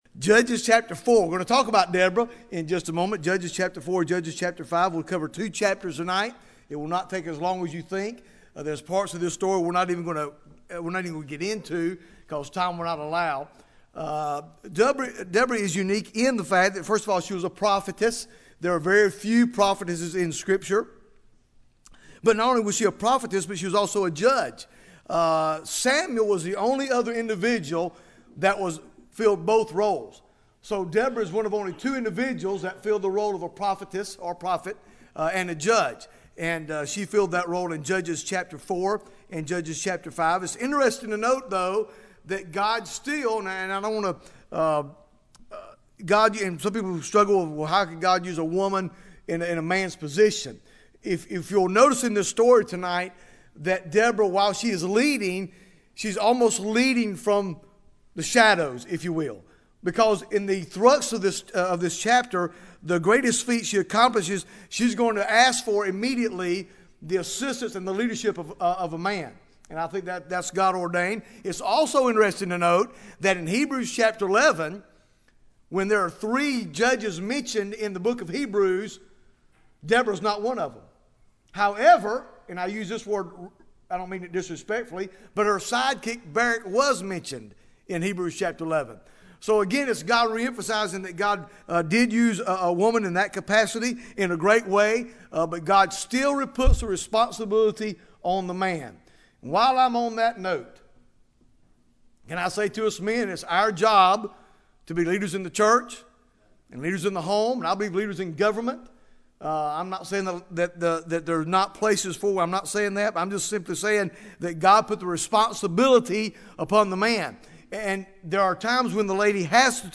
Bible Text: Judges 4 | Preacher